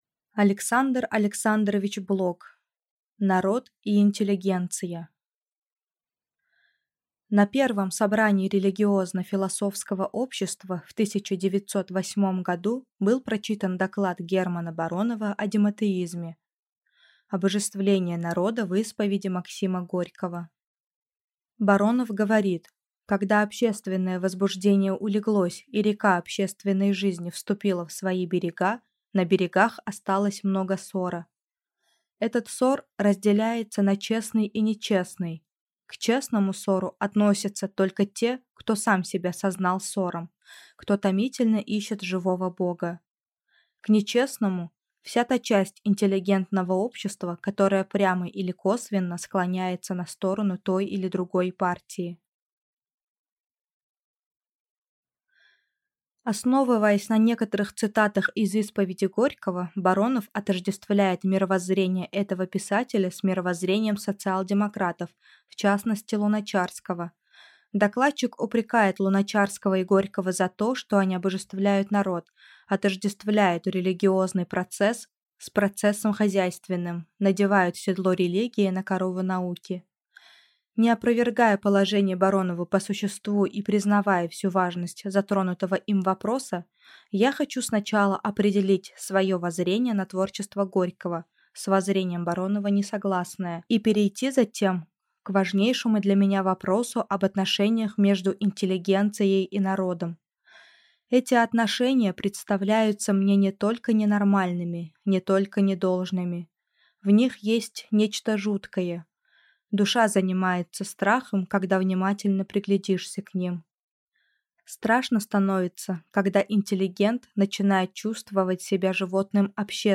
Аудиокнига Народ и интеллигенция | Библиотека аудиокниг